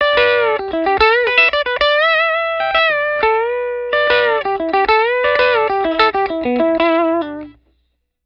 TELE-BRIDGE-LICK-2-ML4.wav